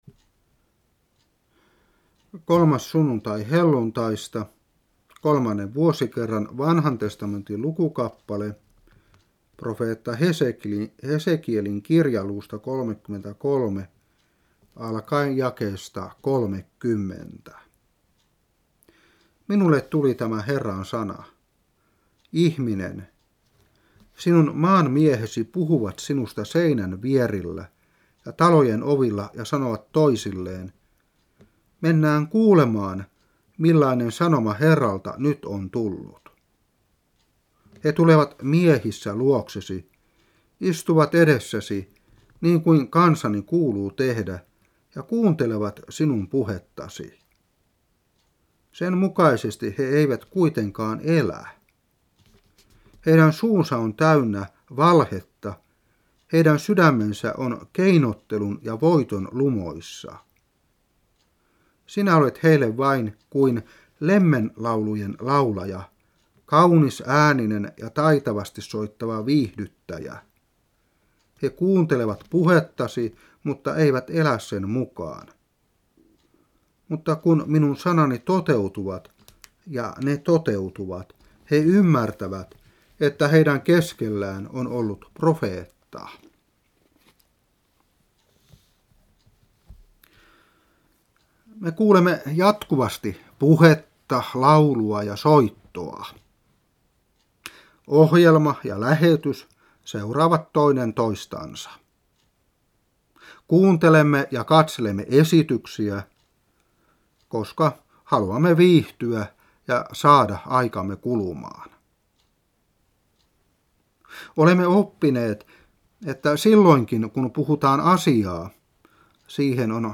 Saarna 2015-6.